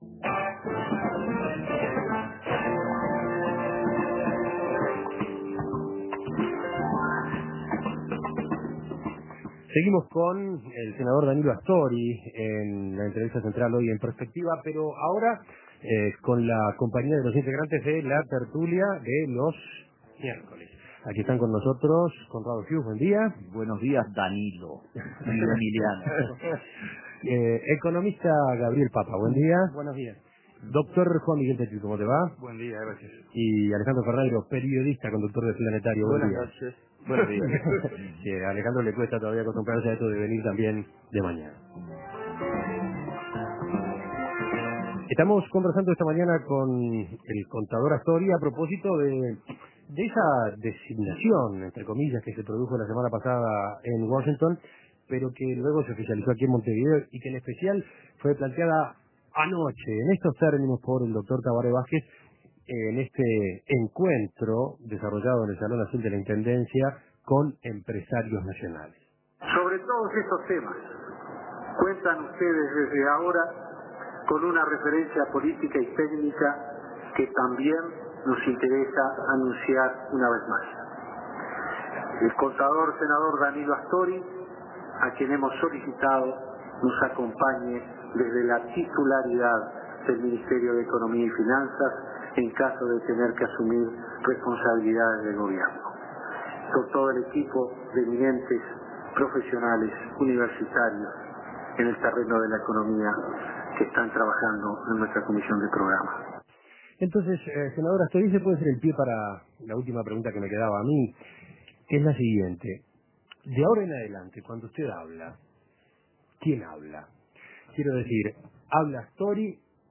La economía en un posible gobierno de izquierda. Los contertulios dialogan con el senador Danilo Astori